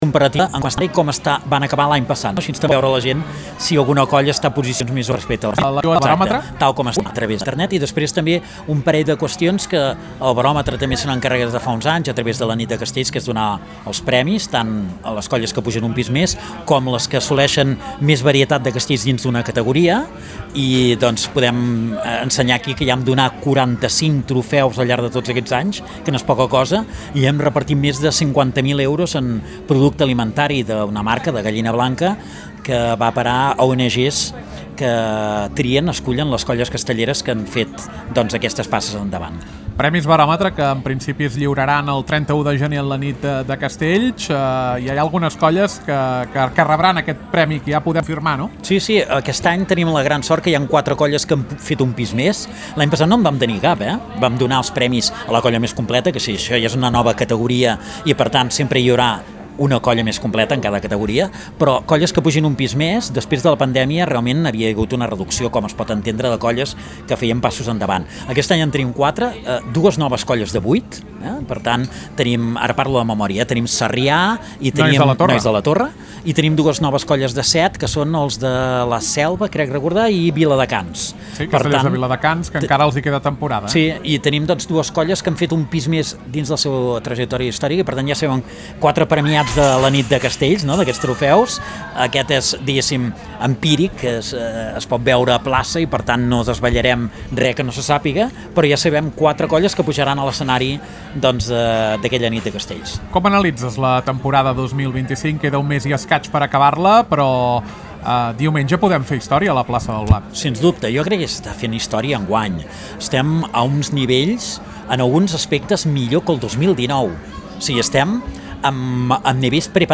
Recupera un dels primers esdeveniments clau de la Fira de Santa Úrsula 2025 a Ràdio Ciutat de Valls. Programa especial des de la plaça Sant Jordi sobre la Fira Castells